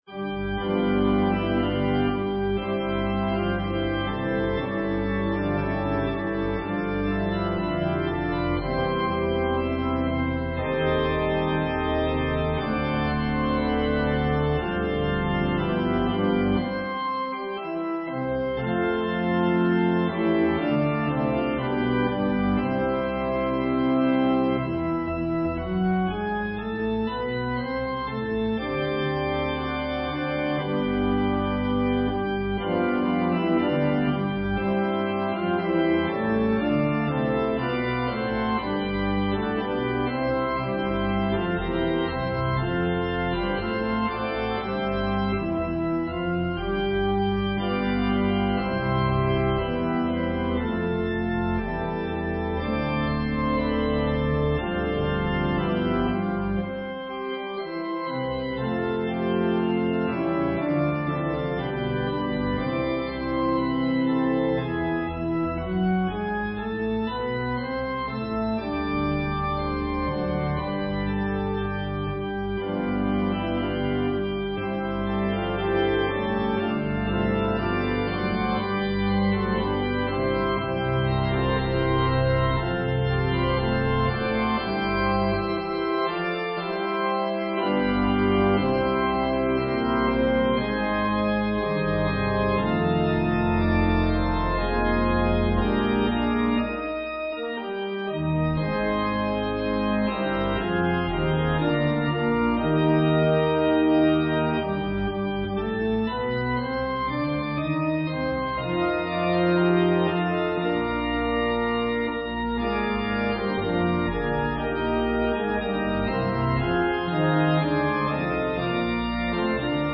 Voicing/Instrumentation: Organ/Organ Accompaniment We also have other 17 arrangements of " Rejoice, the Lord is King!